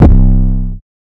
808 [ outside ].wav